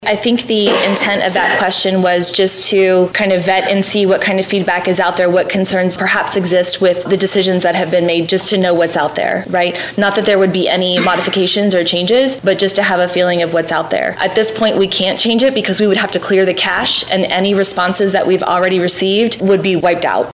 Celina Board of Education Meeting for February 2025